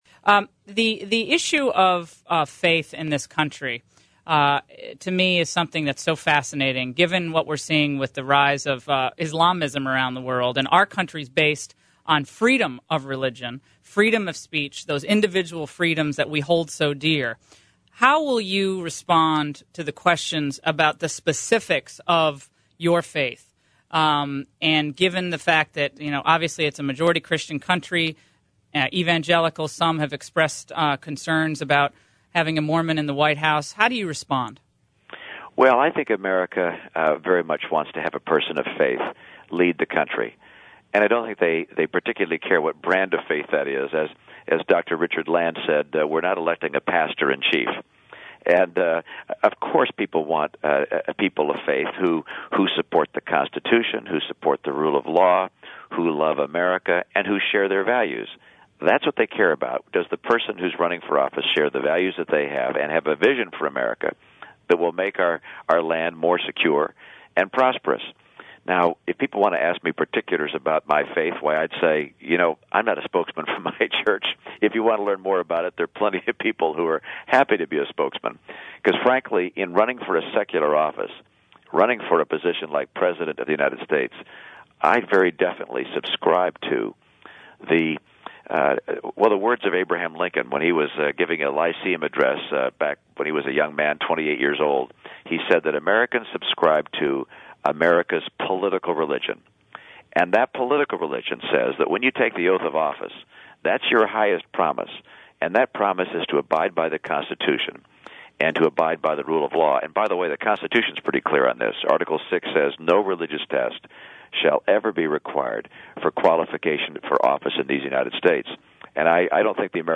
Laura Ingraham asked Romney about it yesterday morning; there is a clip of this portion of the interview availble by